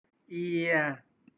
/ia/